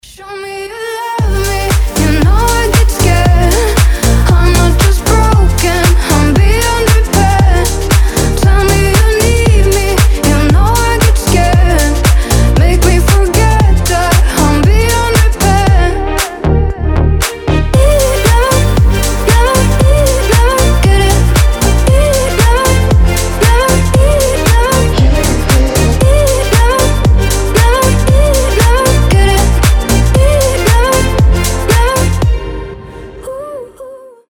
Dance Pop
красивый женский голос
house